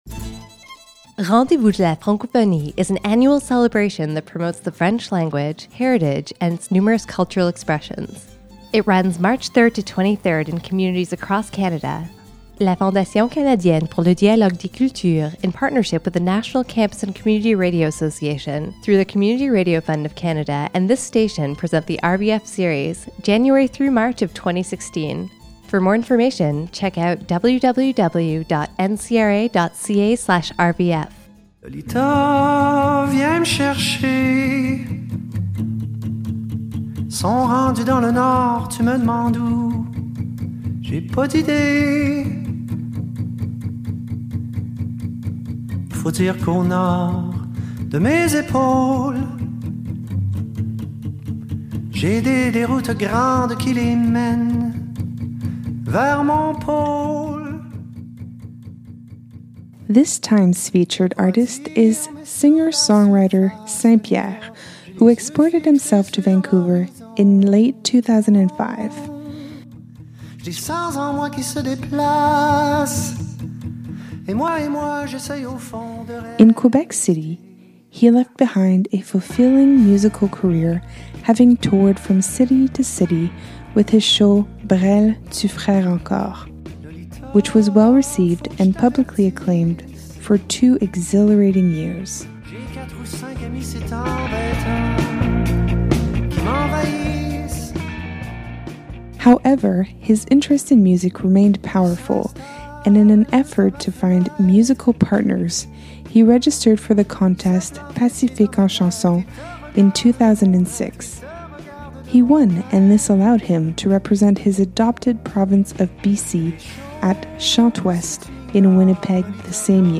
Capsules containing information, interviews, and music from different Western Canadian Francophone artists.